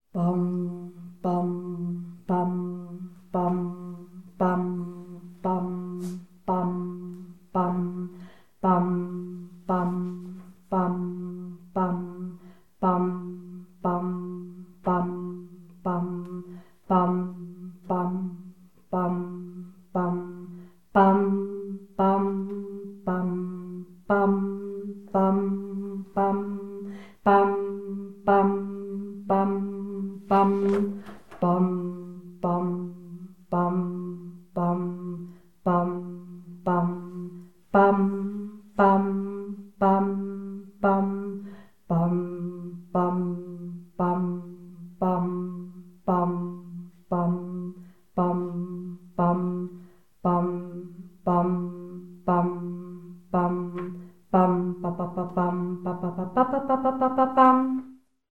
Vianoce 2016 - Zvuk bubnov
zvukbubnov-bass1.mp3